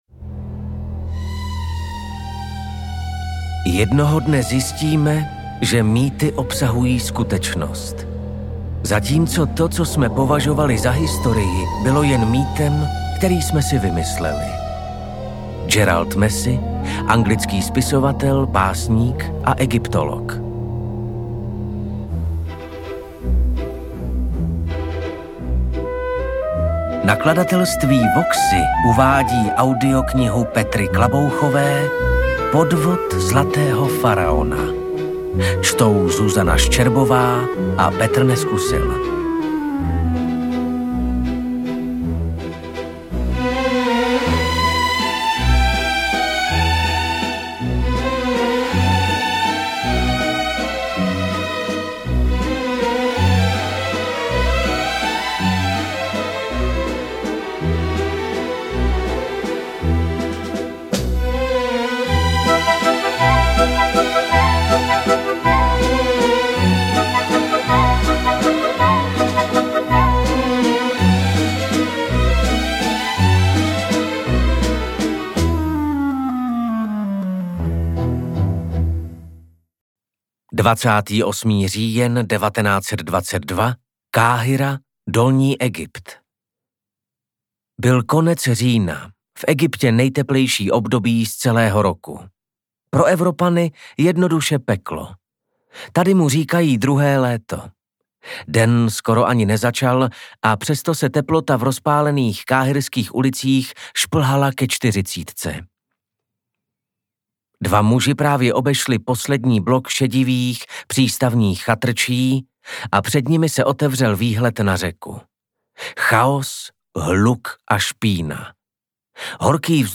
AudioKniha ke stažení, 56 x mp3, délka 15 hod. 58 min., velikost 874,3 MB, česky